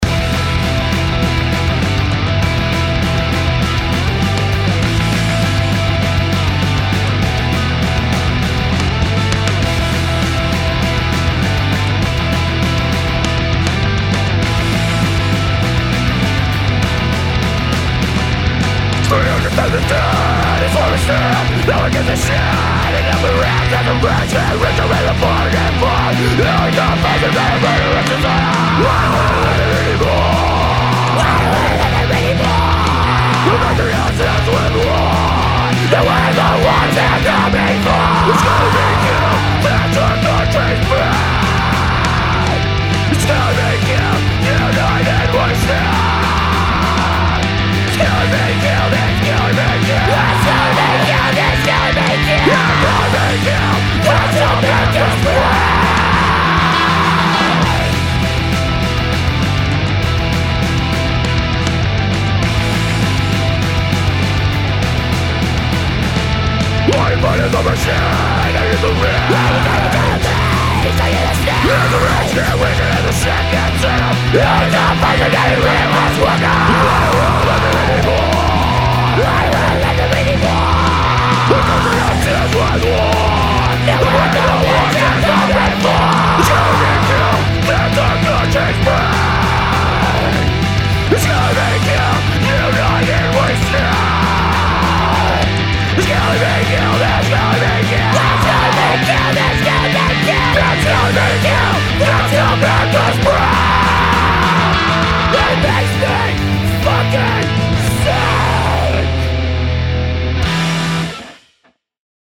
and you will hear three different people singing.